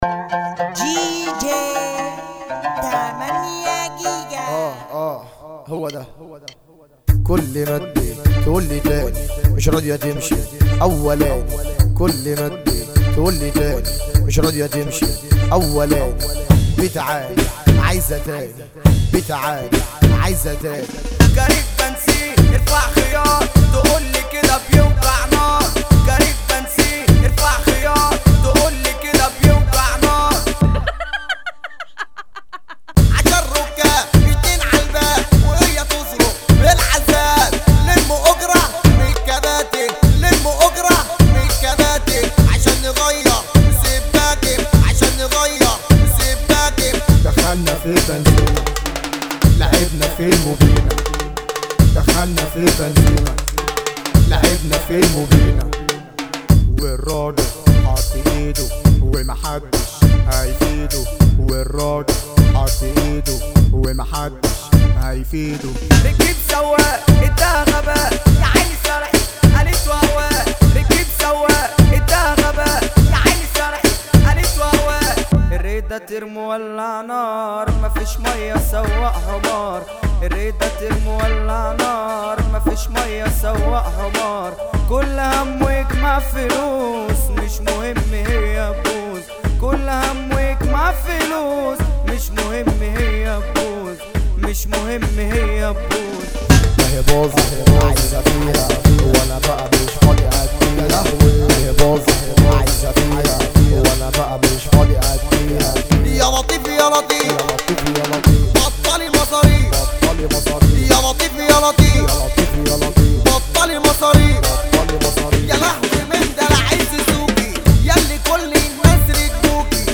مهرجان